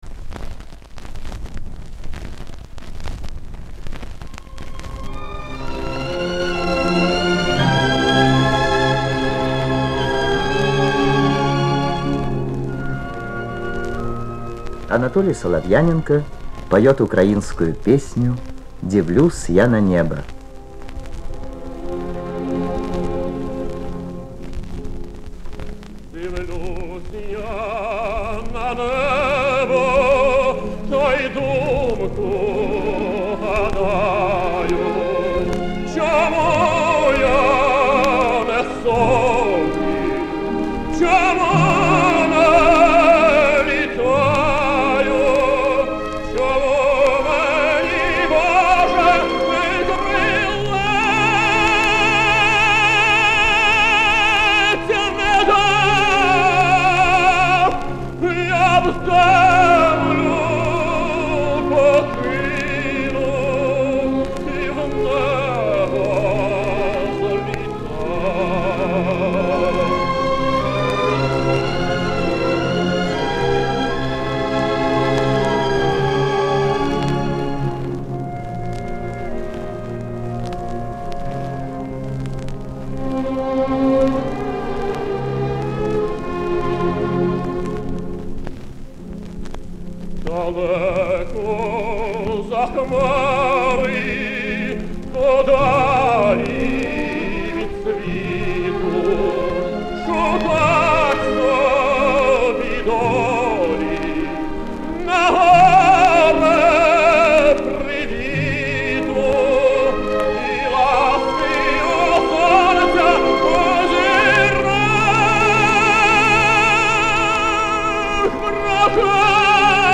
тенор из Донецка.